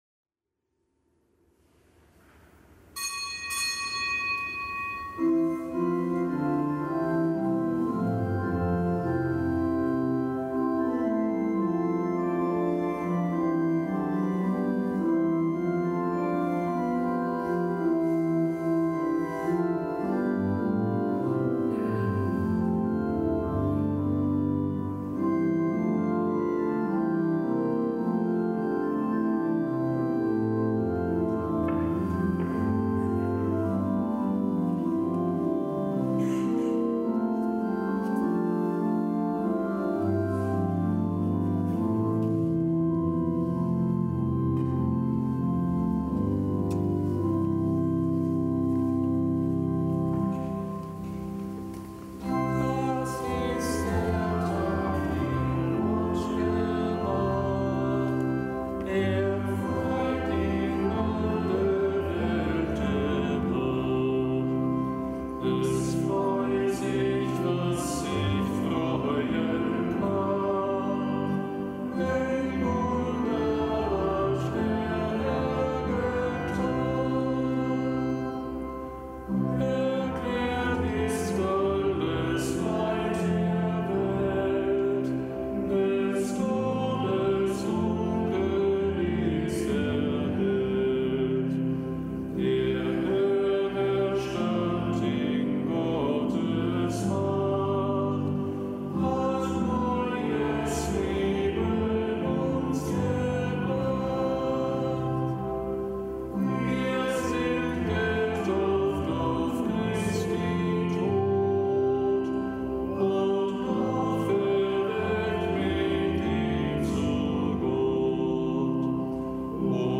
Kapitelsmesse aus dem Kölner Dom am Dienstag der zweiten Osterwoche.